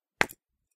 rockHit2.ogg